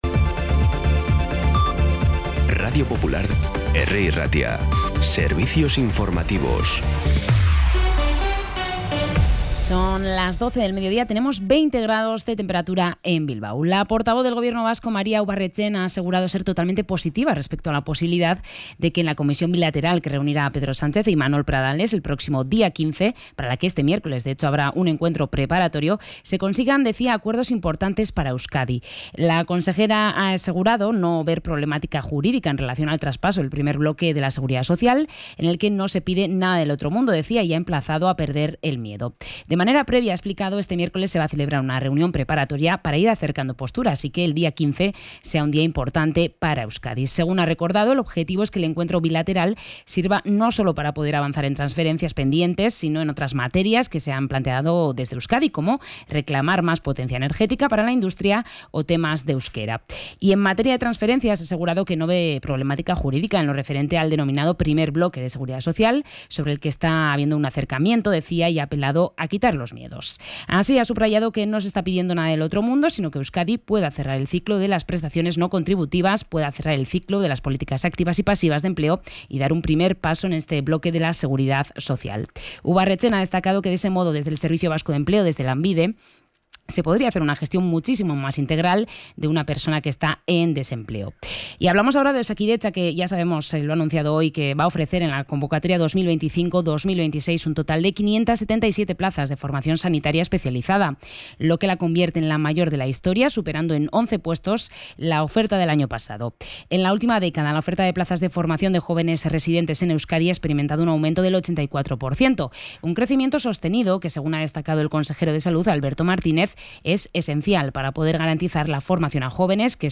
Información y actualidad desde las 12 h de la mañana